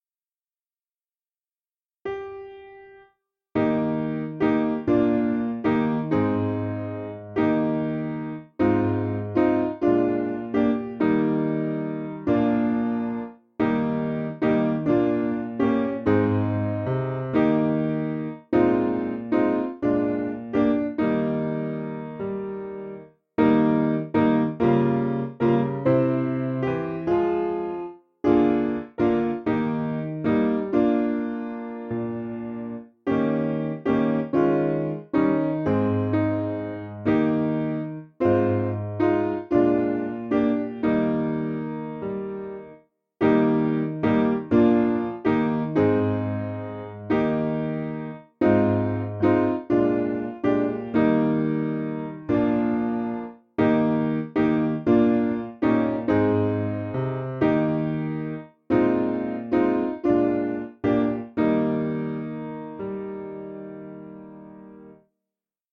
Simple Piano
Single note introduction   385.5kb